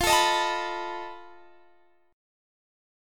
Listen to F7b5 strummed